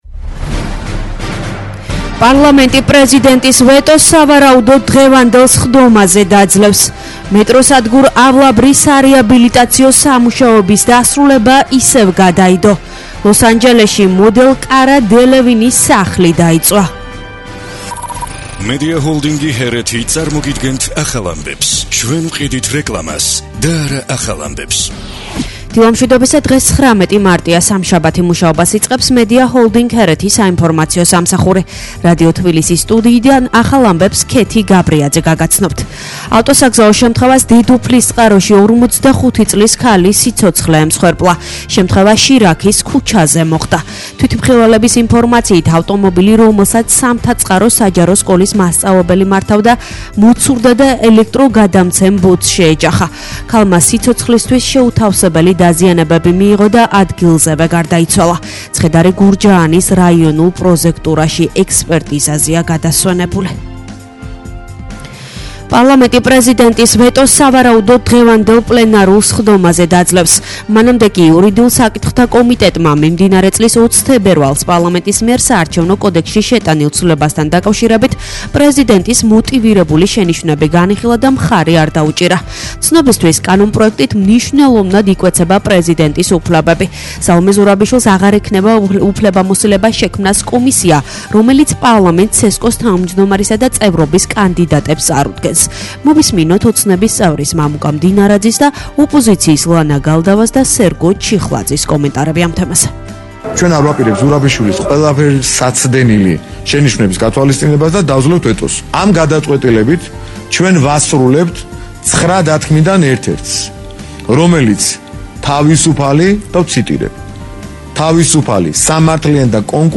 ახალი ამბები 09:00 საათზე